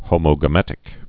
(hōmō-gə-mĕtĭk)